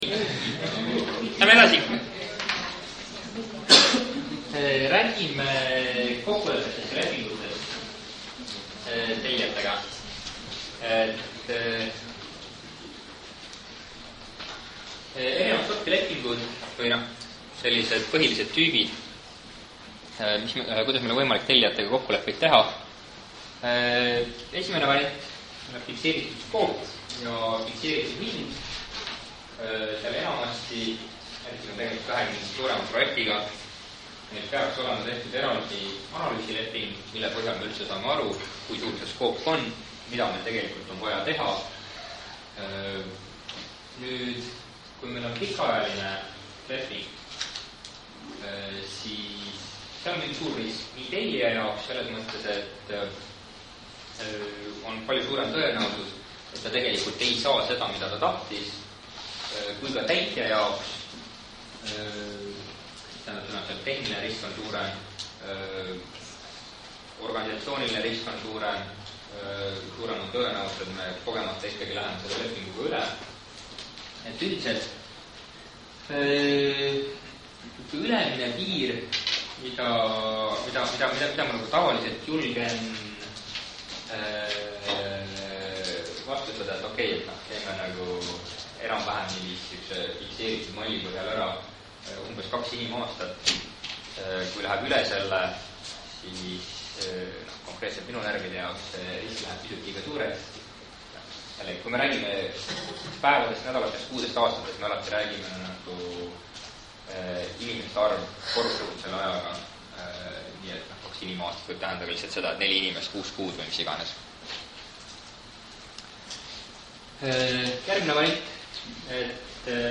projektijuhtimise_loeng_4_3.mp3